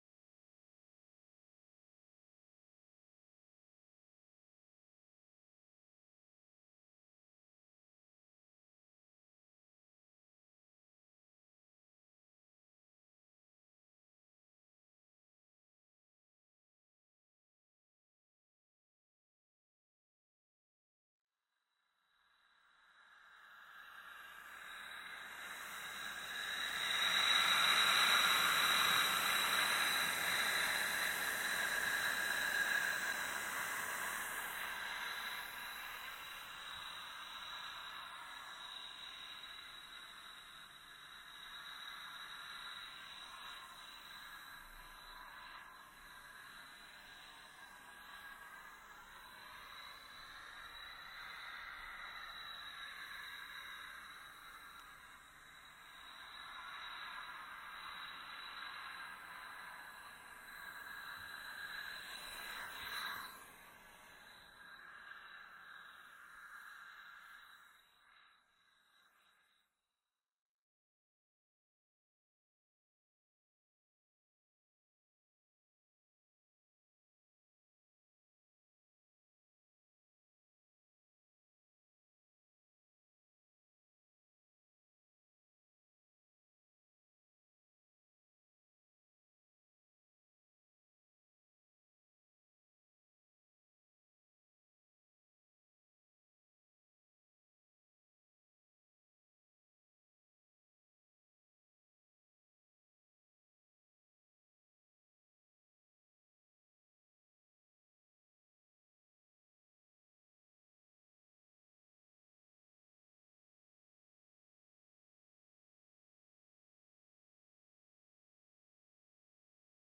sound art